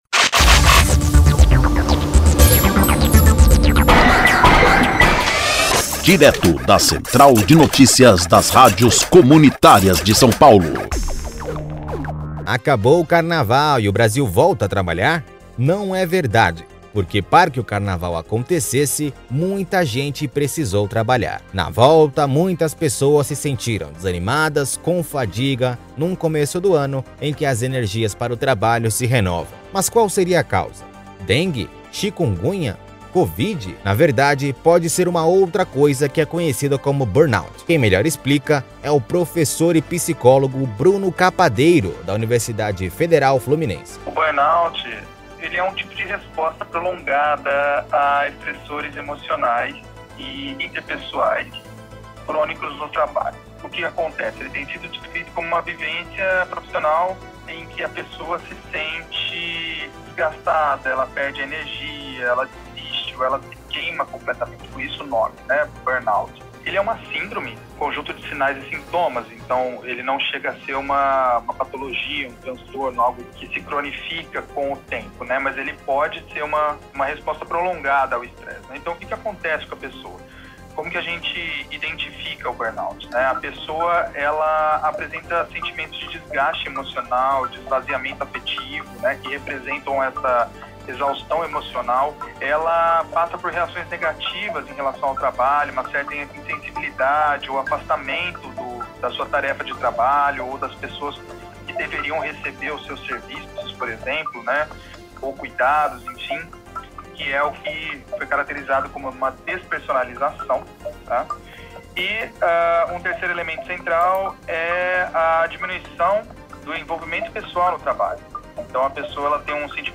Se ao ouvir o professor voce se identificar com os sintomas procure ajuda para um diagnóstico preciso.
Os conteúdos ditos pelos entrevistados não refletem a opinião da emissora.